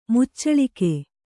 ♪ muccaḷike